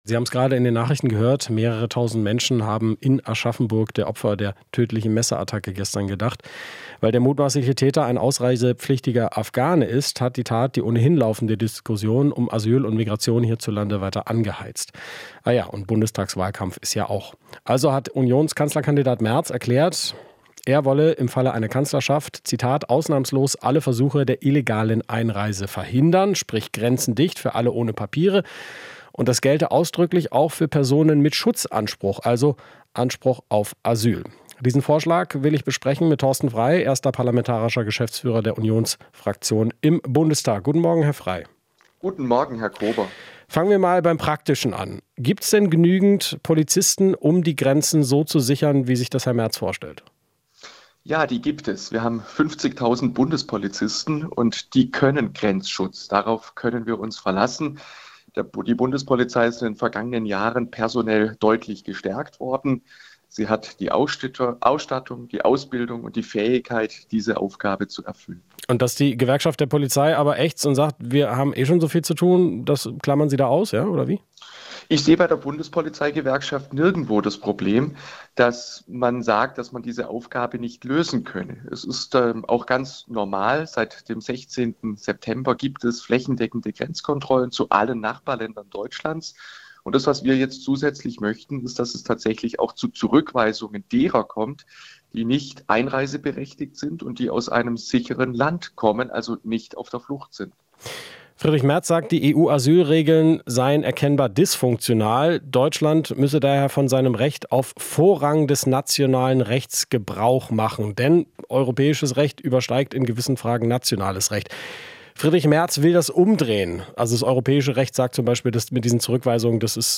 Interview - Frei (CDU): EU-System für Migration funktioniert nicht